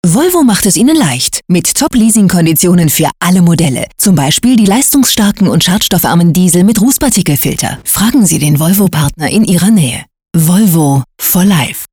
Vielseitige Profi-Sprecherin deutsch: Werbung, TV-Trailer und voice over für VOX, Phoenix.
Sprechprobe: Werbung (Muttersprache):
german female voice over artist.